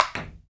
Index of /90_sSampleCDs/Roland L-CD701/PRC_FX Perc 1/PRC_Ping Pong